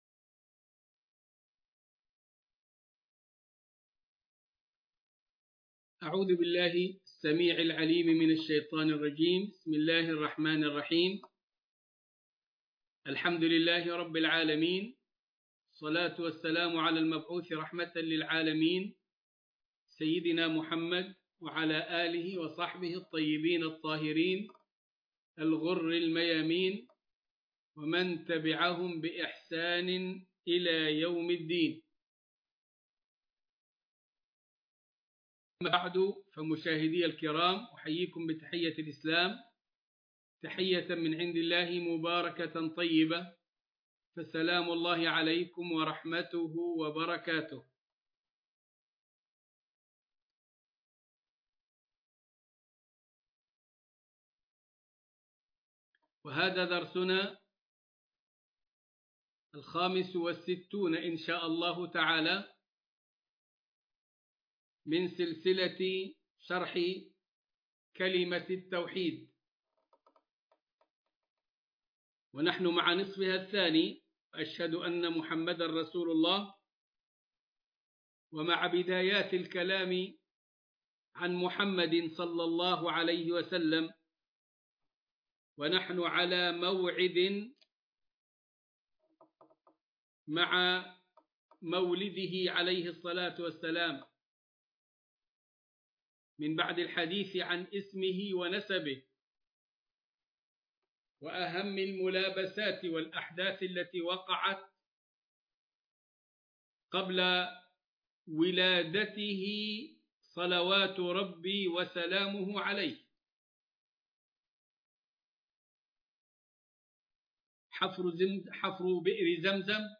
المحاضرة الخامسة والستون